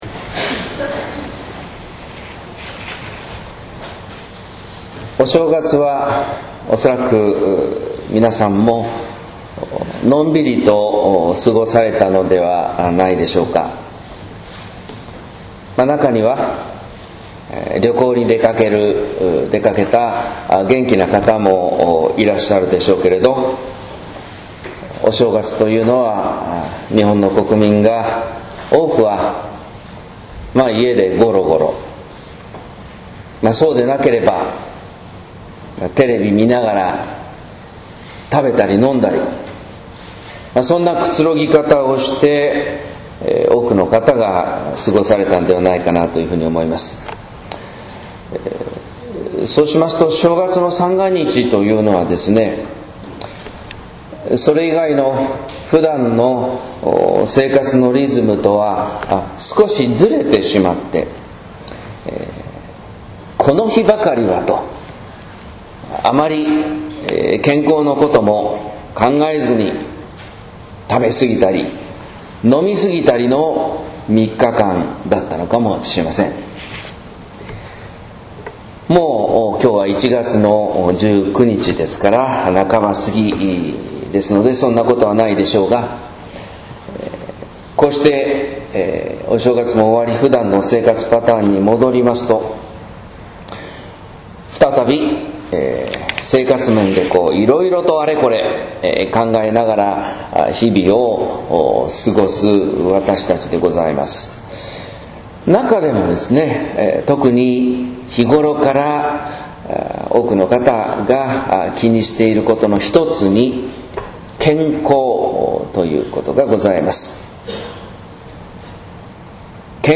説教「近づき、成った天の国」（音声版） | 日本福音ルーテル市ヶ谷教会